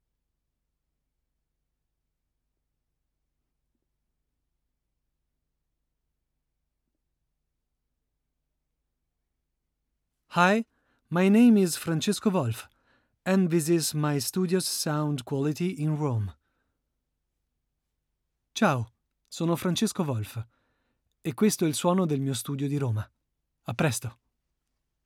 Male
30s, 40s
Bright, Character, Conversational, Friendly, Natural, Reassuring, Versatile, Warm
Voice reels
Microphone: U87 - Brauner Phantom Classic - Rode PodMic